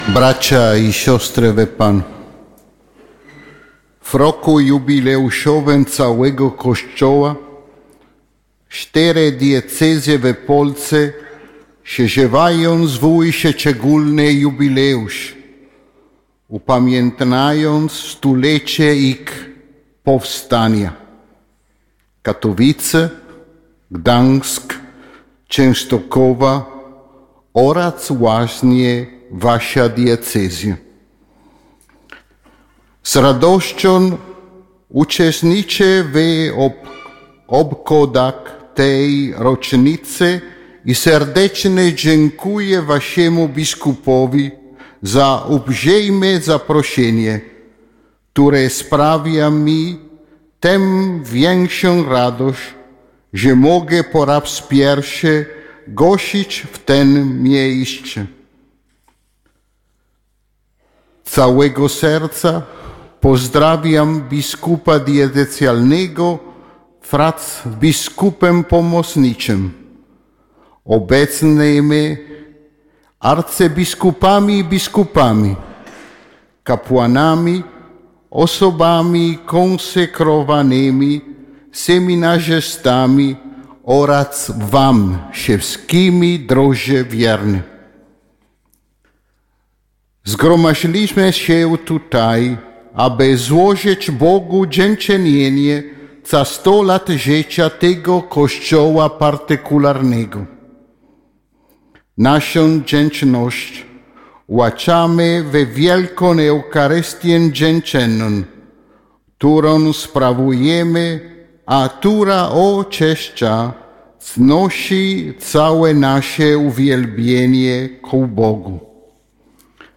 – Kościele Boży pielgrzymujący w Łomży, niech to stulecie będzie dla ciebie okazją do odnowienia zobowiązania, by zawsze patrzeć na swojego oblubieńca i Pana – mówił w homilii Nuncjusz Apostolski w Polsce abp Antonio Guido Filipazzi podczas uroczystej Mszy Świętej w łomżyńskiej katedrze z okazji 100-lecia naszej Diecezji.
Kazanie-.mp3